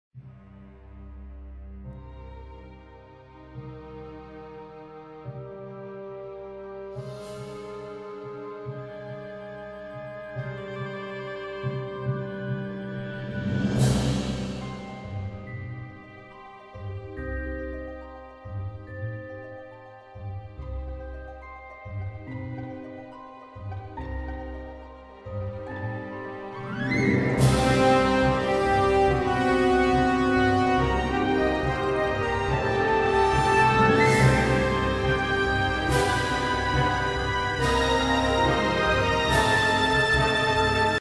Dangerous